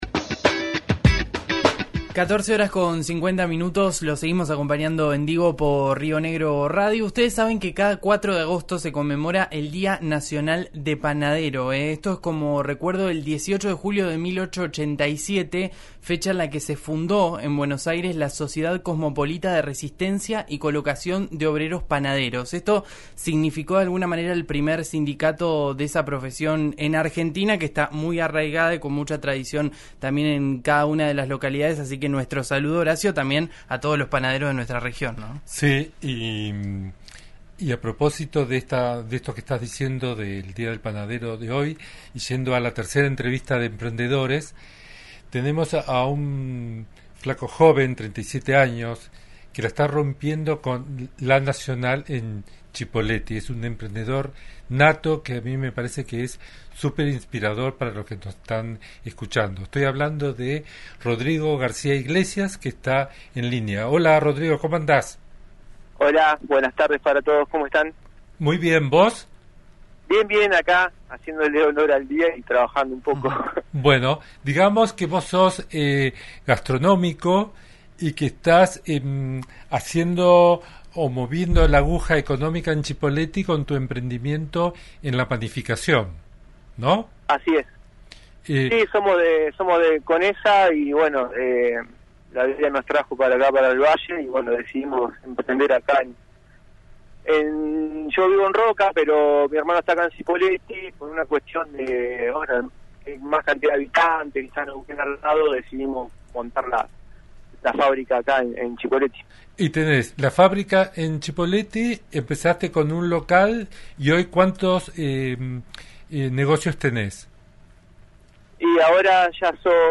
En el día del panadero charlamos con él en RÍO NEGRO RADIO. Escuchá la entrevista: